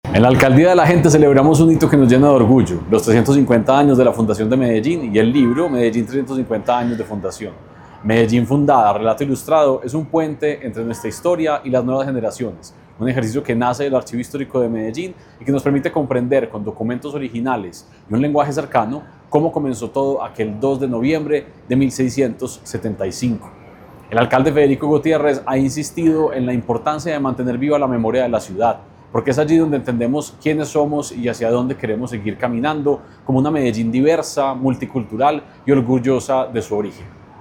Declaraciones del secretario de Cultura Ciudadana, Santiago Silva Jaramillo
Declaraciones-del-secretario-de-Cultura-Ciudadana-Santiago-Silva-Jaramillo.mp3